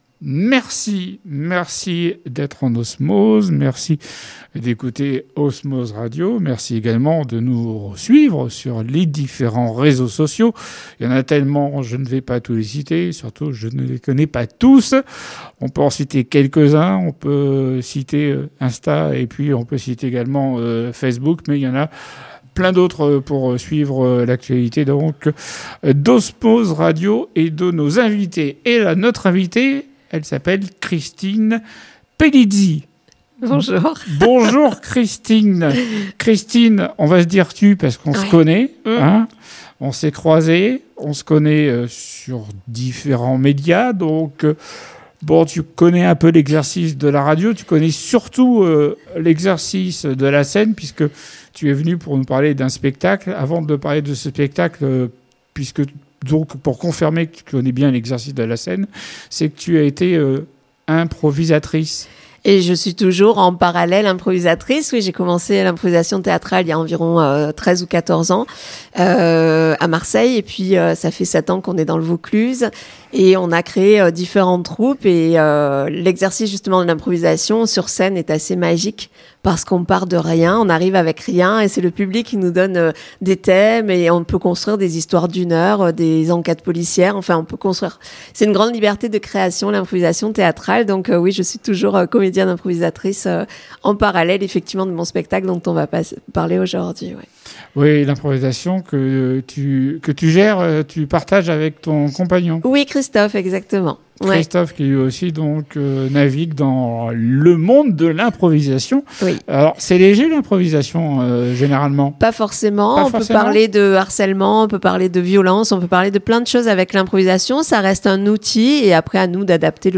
Culture/Loisirs Interviews courtes